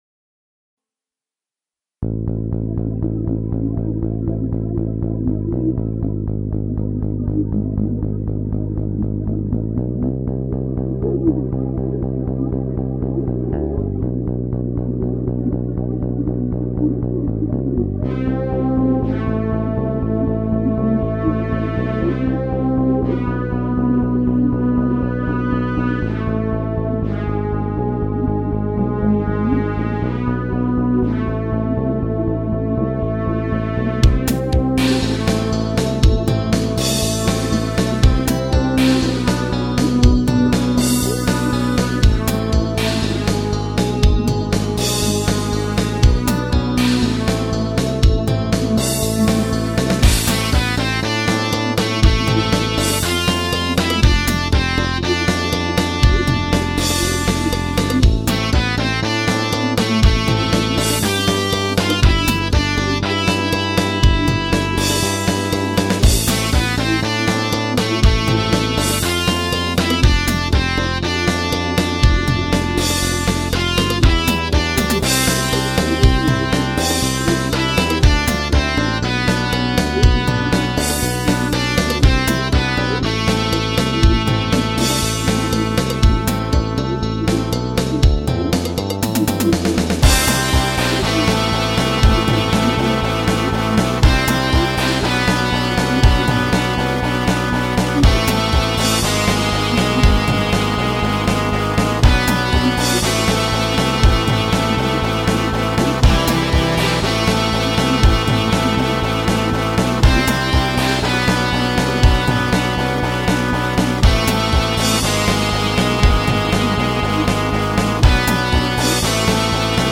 PROGRESSIVE ROCK MUSIC ; WALTZ MUSIC